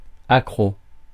Ääntäminen
IPA : /hʊkt/